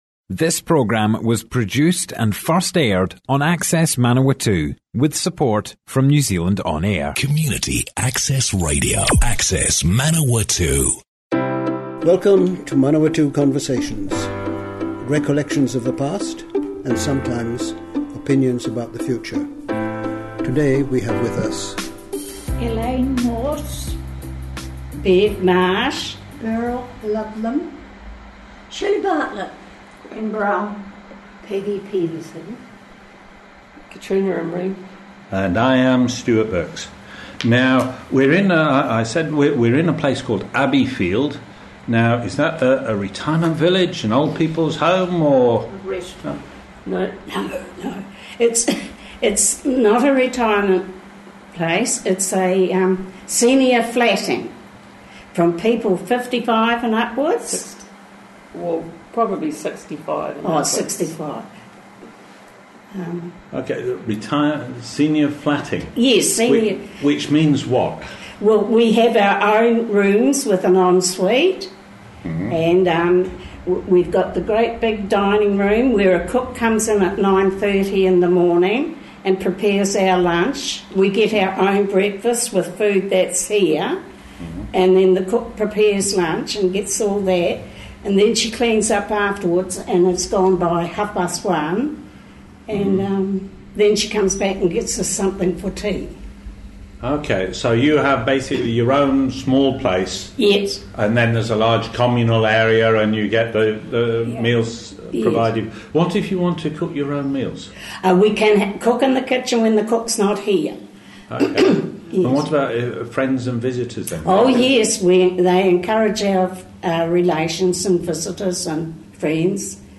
00:00 of 00:00 Add to a set Other Sets Description Comments Abbeyfield residents - Manawatu Conversations More Info → Description Broadcast on Access Manawatu, 5 June 2018, Abbeyfield residents talk about Abbeyfield, a residence offering “senior flatting”.